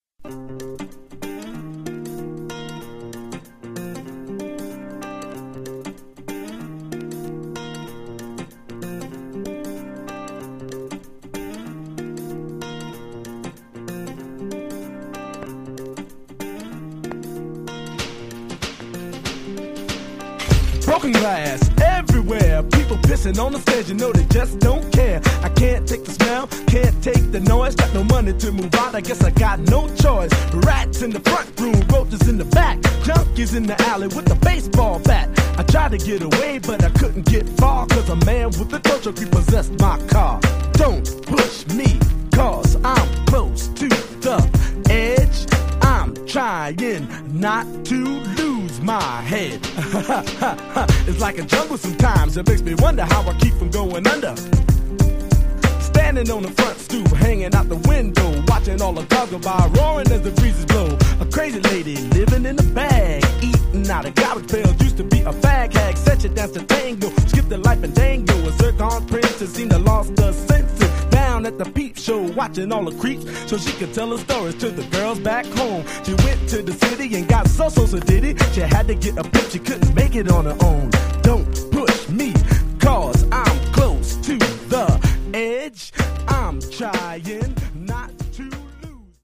95 bpm
Dirty Version